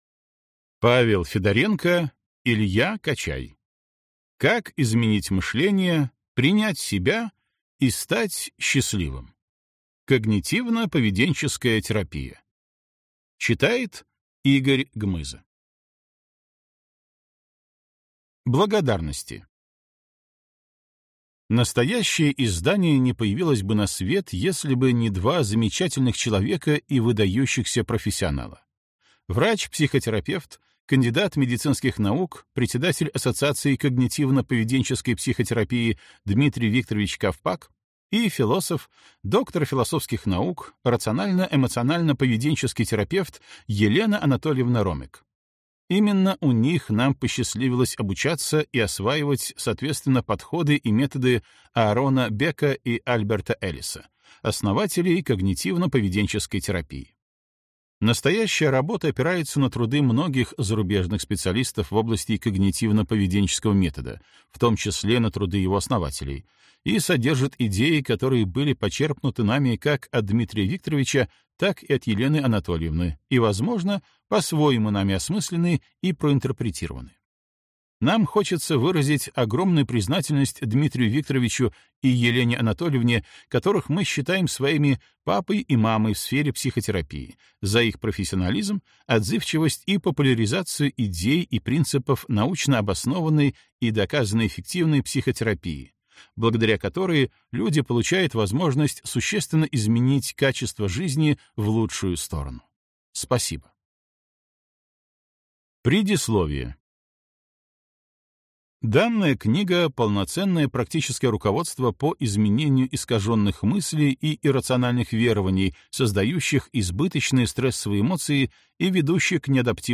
Аудиокнига Как изменить мышление, принять себя и стать счастливым. Когнитивно-поведенческая терапия | Библиотека аудиокниг